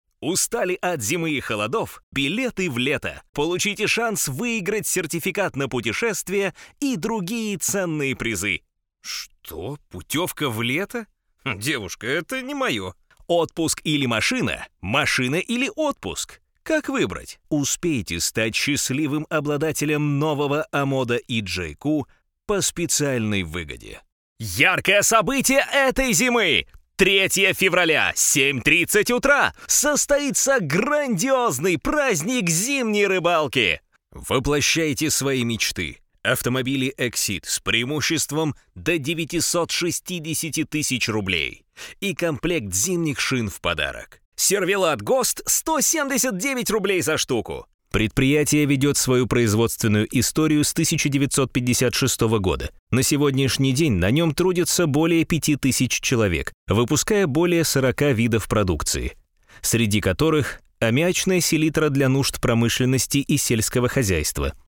Богатейший опыт озвучивания аудиорекламы. Если нужен классный молодой, голос или энергичный баритон - вы его нашли.